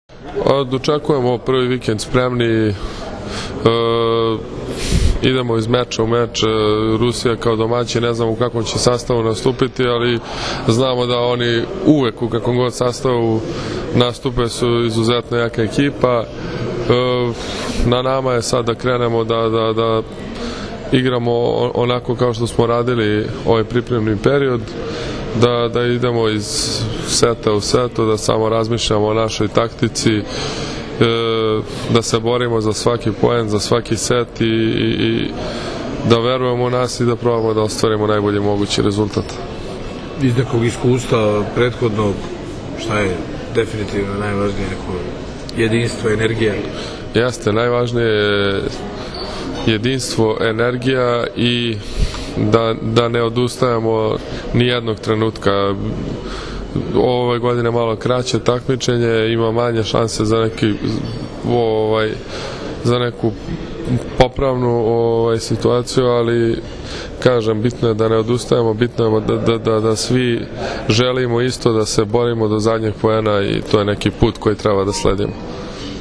Treneri na konferenciji za novinare – Srbija u petak (19,10) protiv Rusije